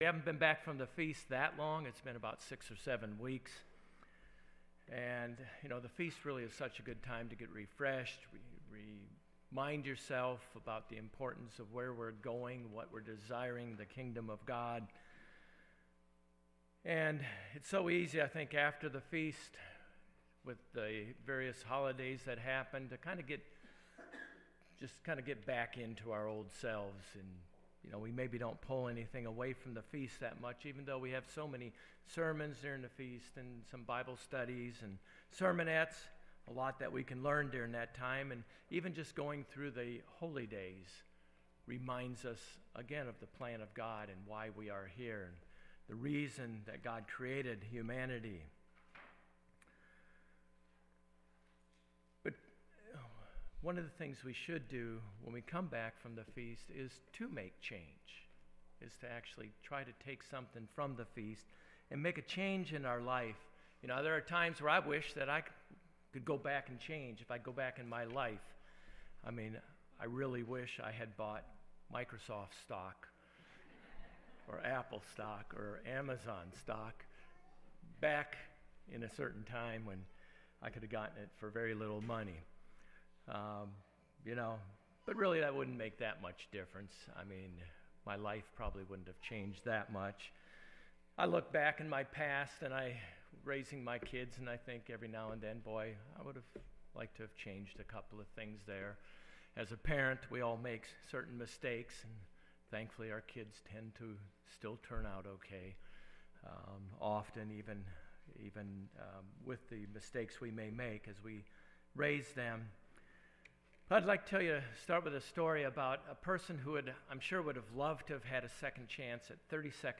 In this message we explore these three steps.